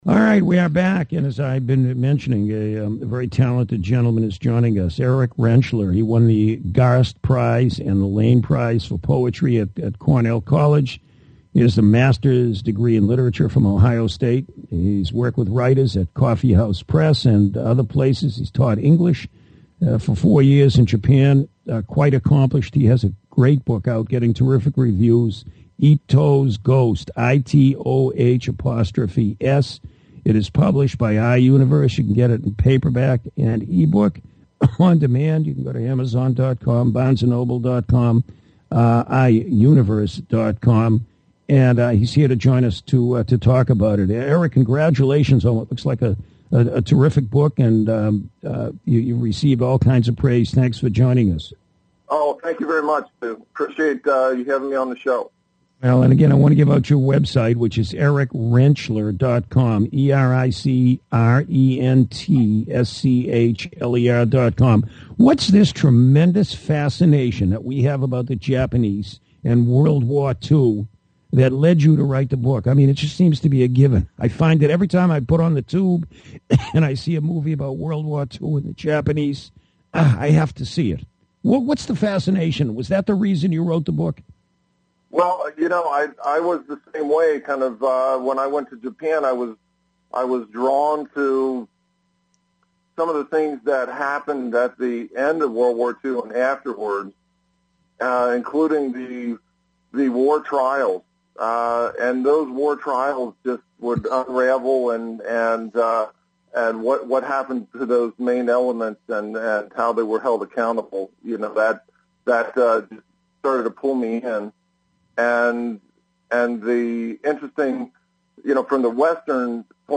Money Matters Radio Network Radio interview